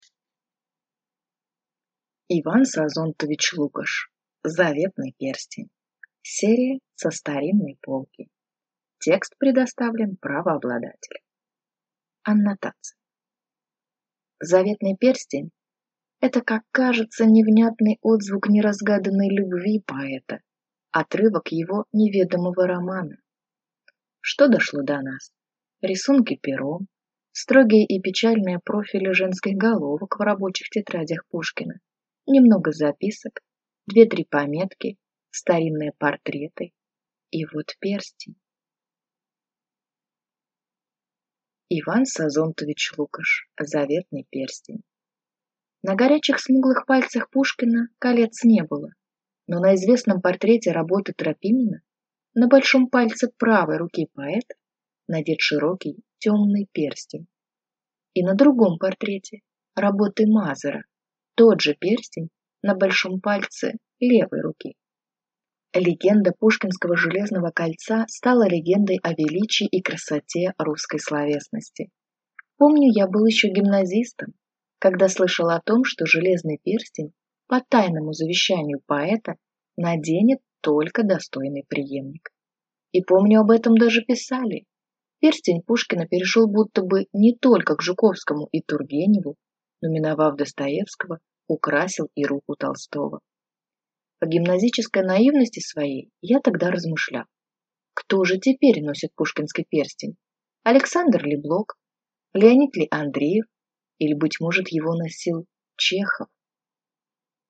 Аудиокнига Заветный перстень | Библиотека аудиокниг